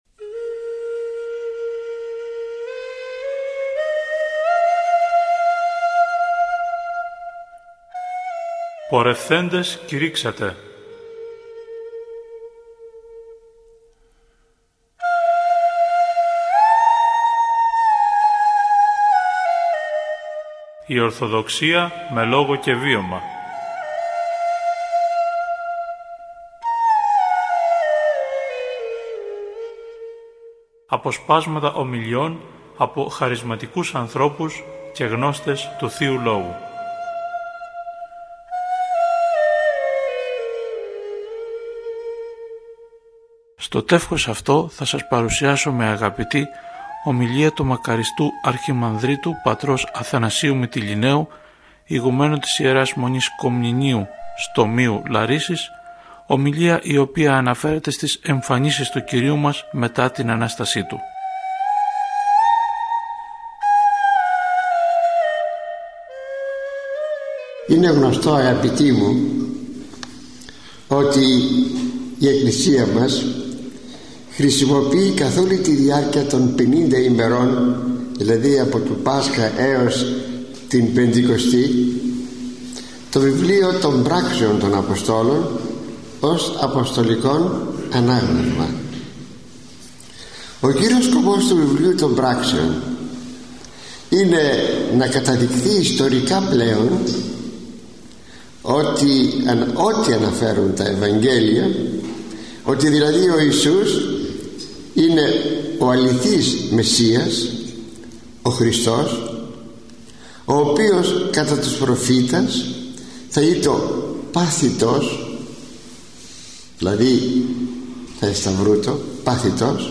“Πορευθέντες κηρύξατε”: οι εμφανίσεις του Κυρίου μετά την Ανάστασή Του – ηχογραφημένη ομιλία